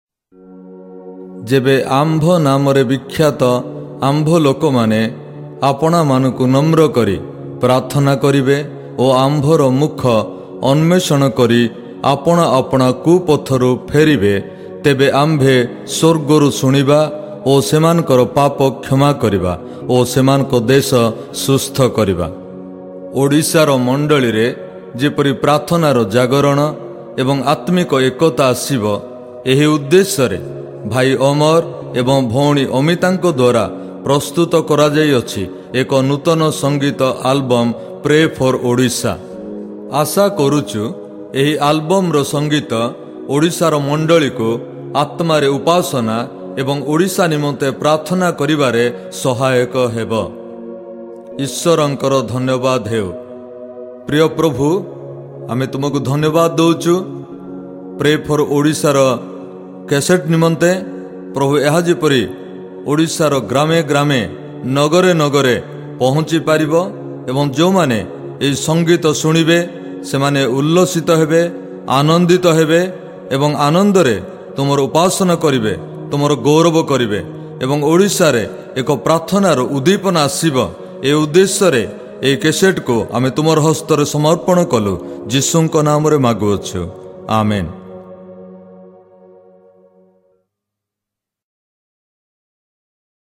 prayer and worship album